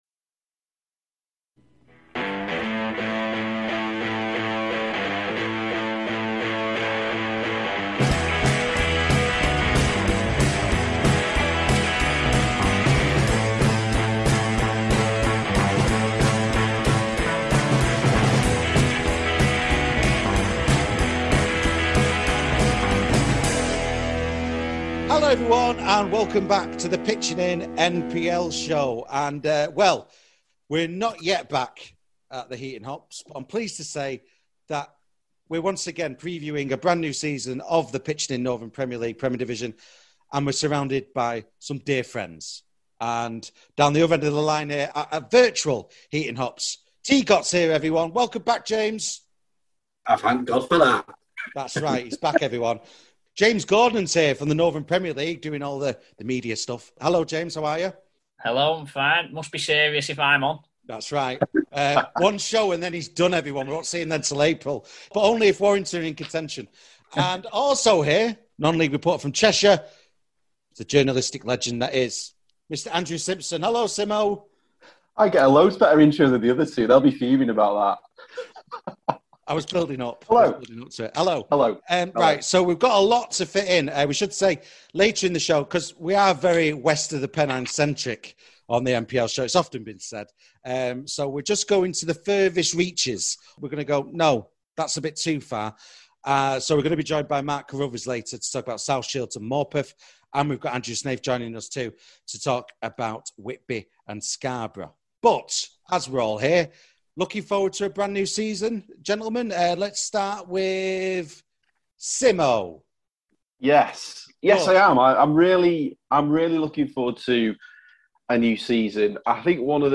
This programme was recorded at a virtual Heaton Hops on Thursday 12th August 2021.